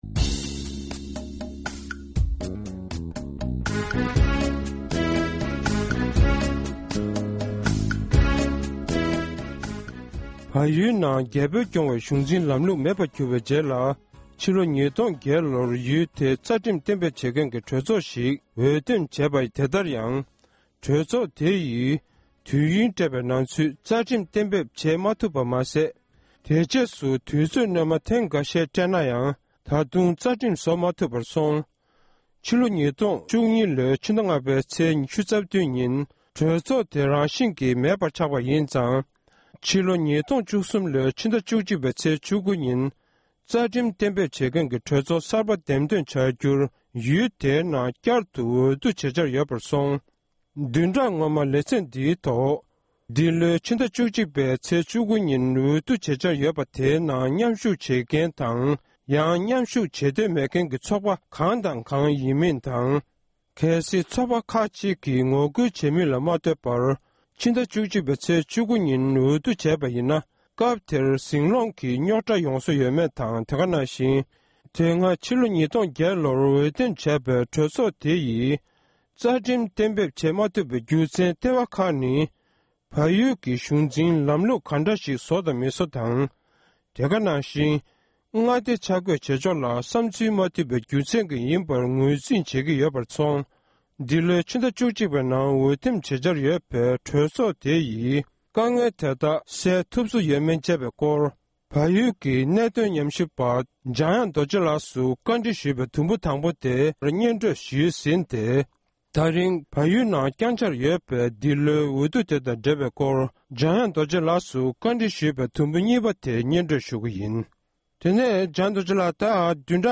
གནས་འདྲི་ཞུས་པའི་གཟའ་འཁོར་སྔོན་པའི་འཕྲོས་དེར་གསན་རོགས༎